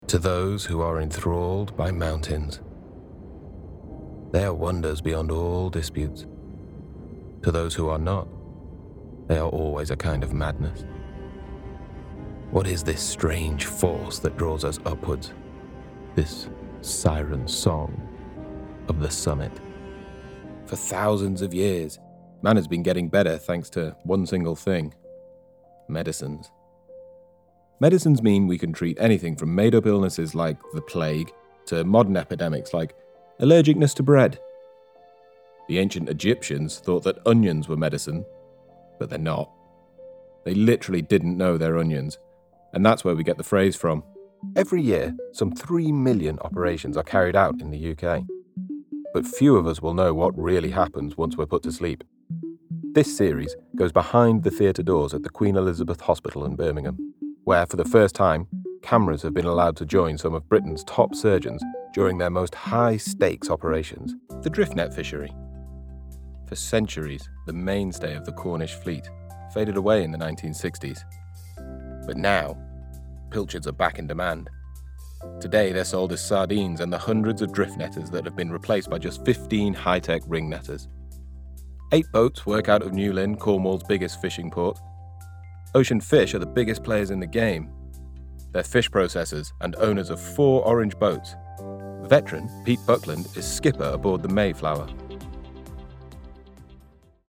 Narration Voice Actors for Training Videos
It would be warm, natural, informative or serious, authoritative.
0109_Narration_Showreel.mp3